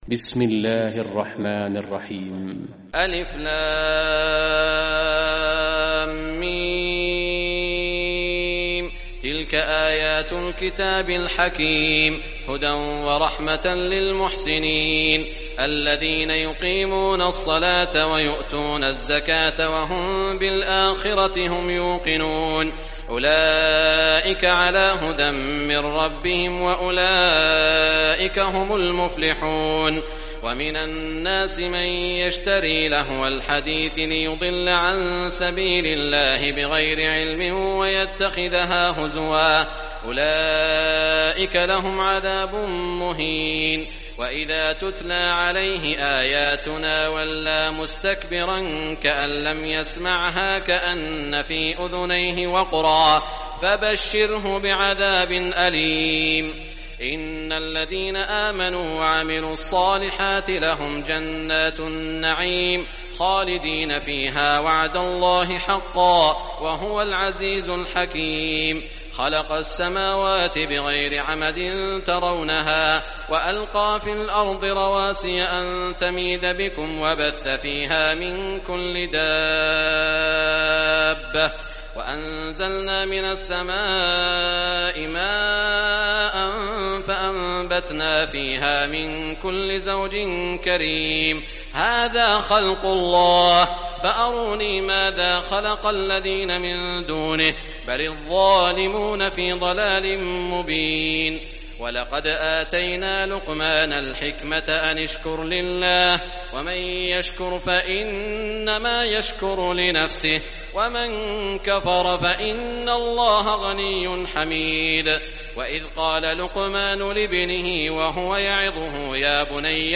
Surah Luqman Recitation by Sheikh Shuraim
Surah Luqman, listen or play online mp3 tilawat / recitation in Arabic in the beautiful voice of Sheikh Suad Al Shuraim.